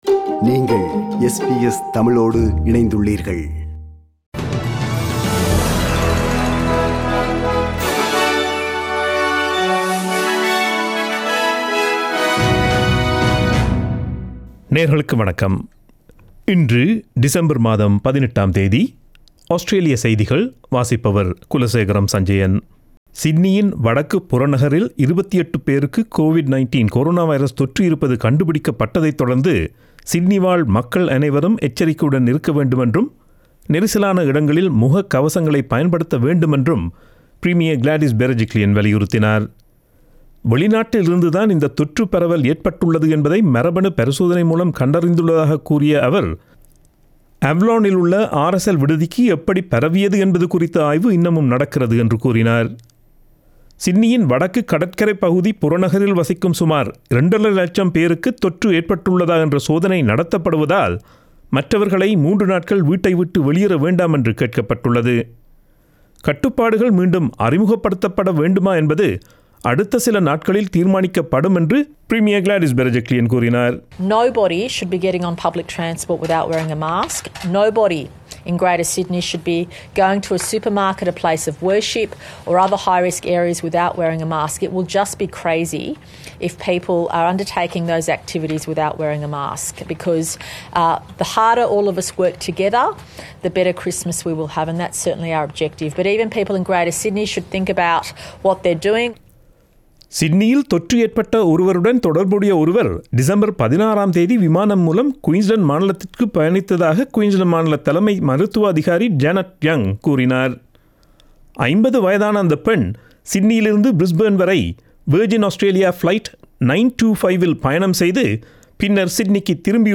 Australian news bulletin for Friday 18 December 2020.